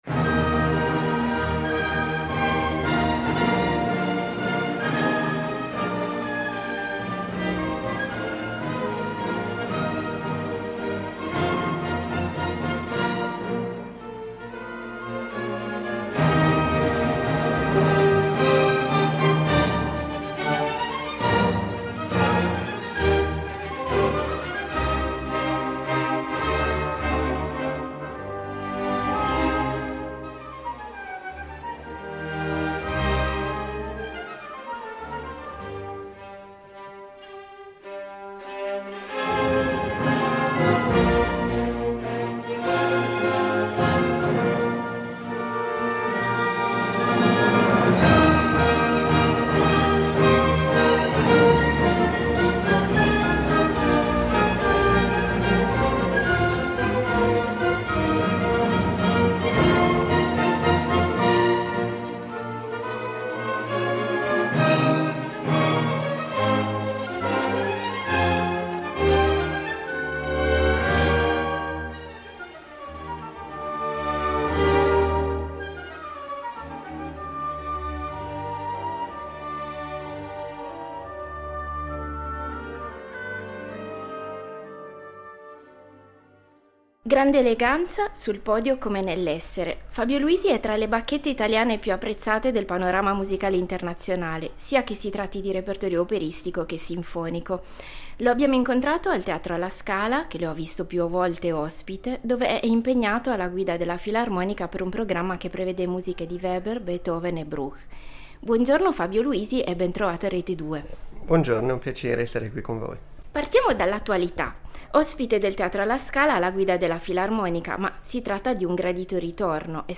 Incontro con Fabio Luisi